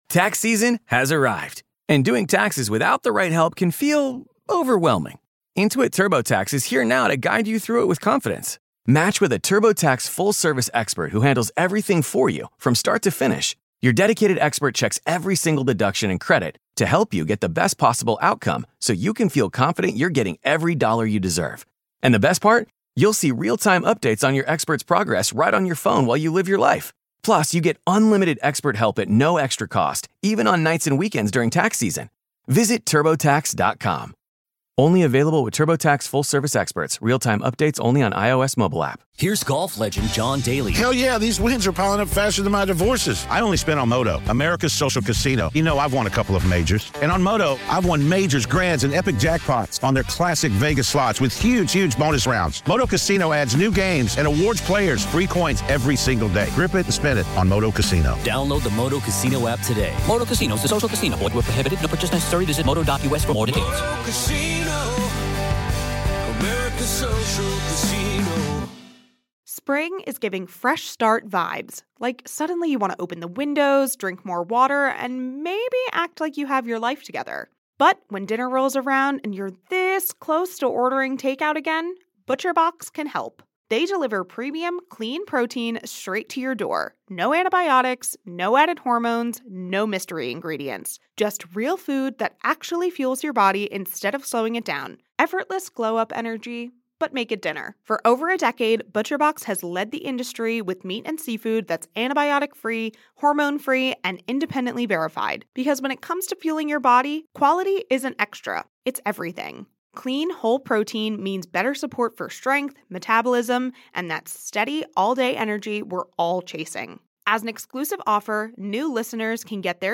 we talk with paranormal investigator